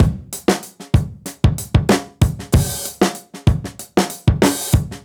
Index of /musicradar/dusty-funk-samples/Beats/95bpm